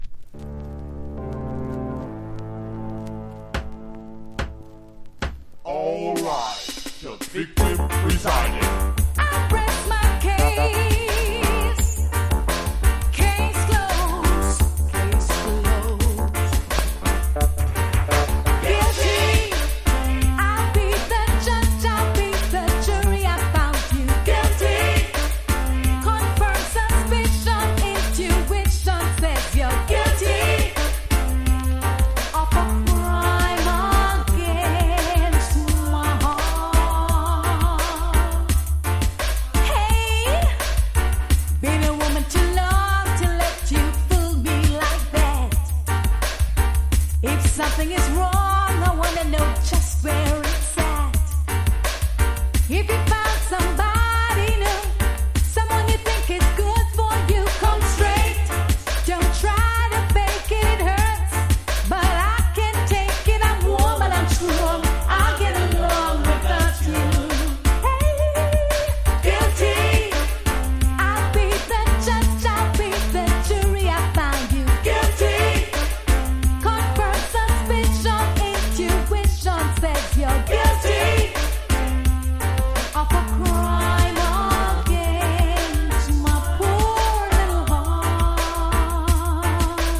• REGGAE-SKA
ステッパーズ・リズムにコンシャスな内容を歌った後世まで語り継がれる名曲のひとつ!!